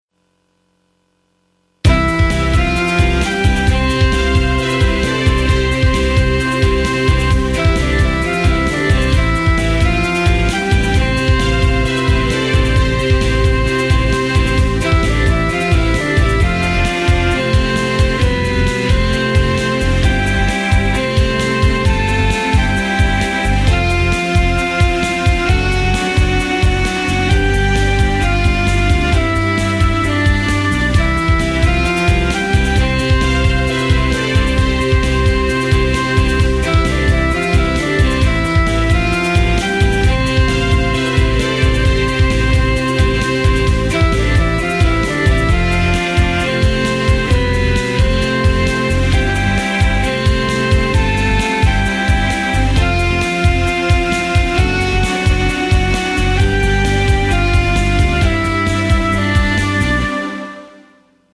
SD-50というハード音源が届いたので、早速それを使用して作ってみた。
ハード音源の特徴なのか、ヘッドホン越しではノイズを確認。